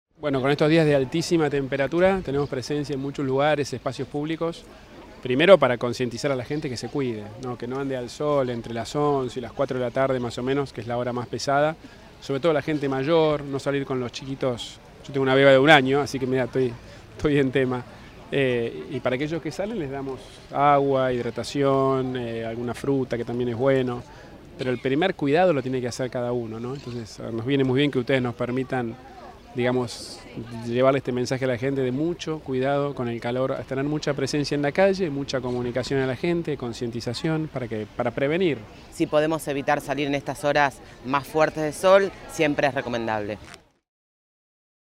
“El primer cuidado lo tiene que hacer cada uno, es importante llevar el mensaje de ‘mucho cuidado con el calor’”, aseveró el Jefe de Gobierno en su visita al puesto móvil de la Plaza Arenales, en el barrio porteño de Villa Devoto, donde estuvo acompañado por la ministra de Desarrollo Humano y Hábitat, Guadalupe Tagliaferri, y el subsecretario de Fortalecimiento Familiar y Comunitario, Maximiliano Corach.